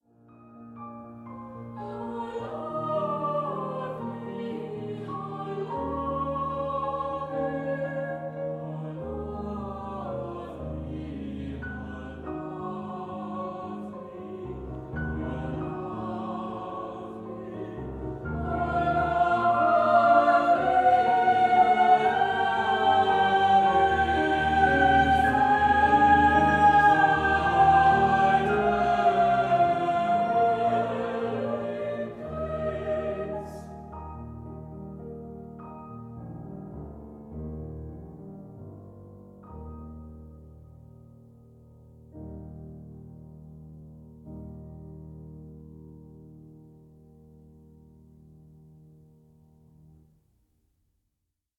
24-bit stereo
soprano
baritone
piano
Recorded 7-9 April 2017 at Trinity Church, Croydon, UK